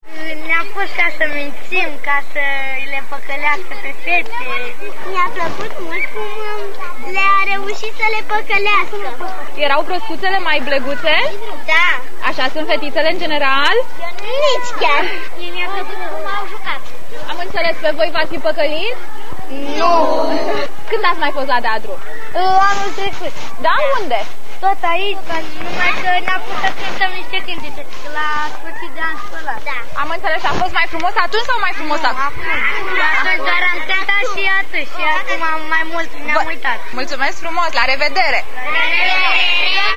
O ora de poveste, cu spectacol de teatru si muzica pe scena casei de cultura din Amara
Copiii au intrat foarte usor in jocul actorilor, au cantat, au aplaudat, si la nevoie chiar au mintit de dragul personajelor preferate: